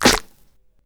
07_Snare_01_SP.wav